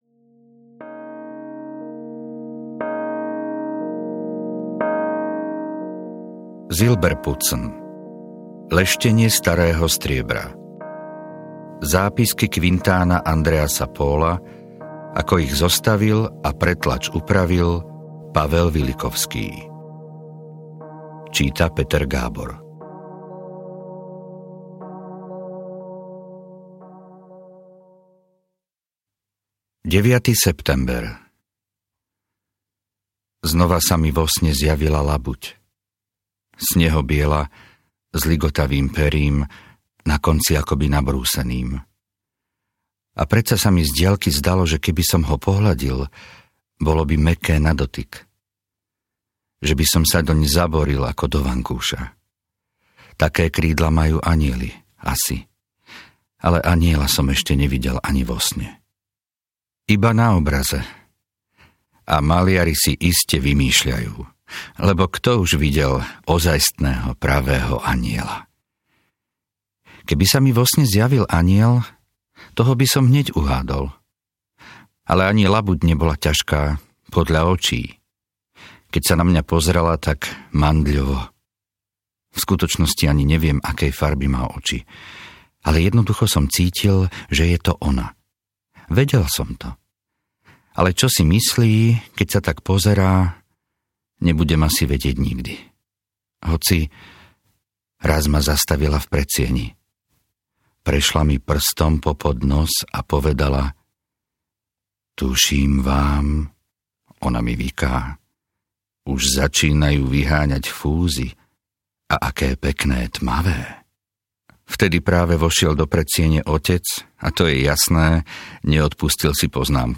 Audio knihaSilberputzen - Leštenie starého striebra
Ukázka z knihy